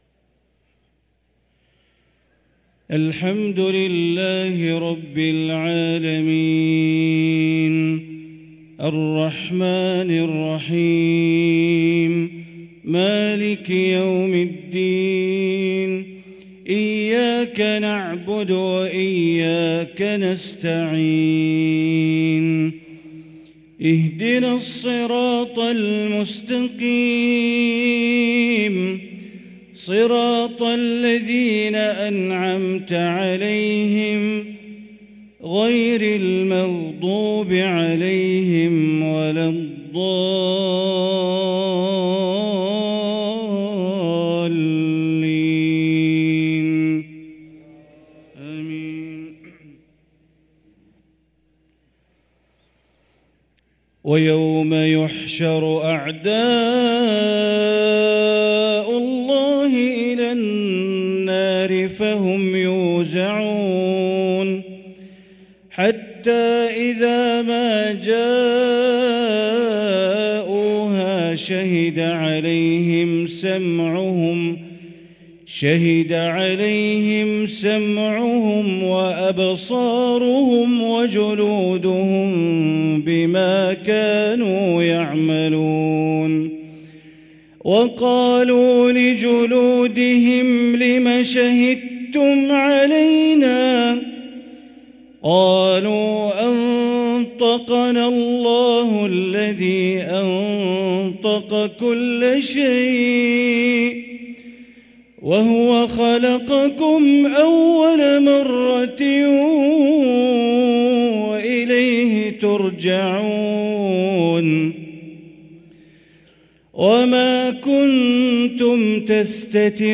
صلاة العشاء للقارئ بندر بليلة 14 شعبان 1444 هـ
تِلَاوَات الْحَرَمَيْن .